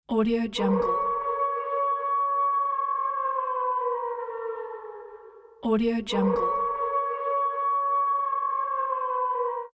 Distant Wolf Howling Bouton sonore
The Distant Wolf Howling sound button is a popular audio clip perfect for your soundboard, content creation, and entertainment.